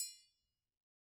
Triangle6-HitM_v1_rr2_Sum.wav